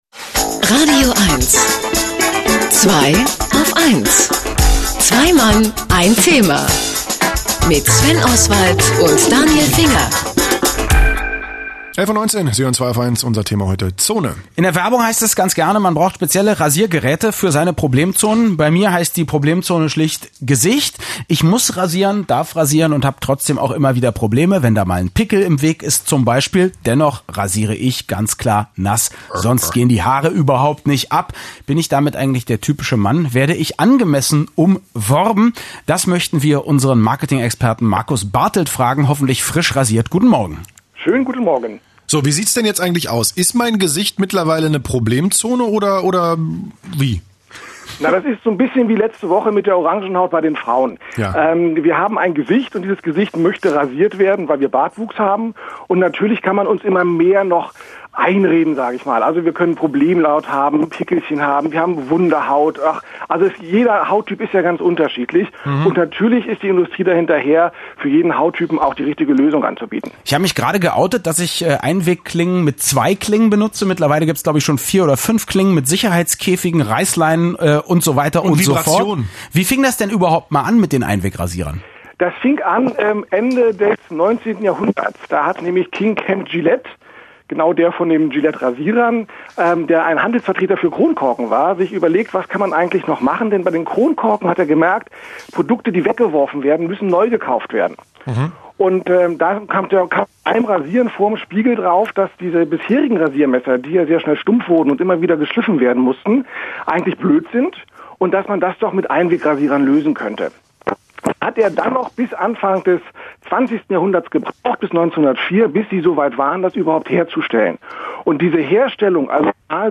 Fast 75 Interviews sind in diesem Zeitraum entstanden – und da ich mich selbst nicht mehr an alle erinnern konnte, werde ich nun in loser Reihenfolge, aber chronolgisch hier die Takes noch einmal posten.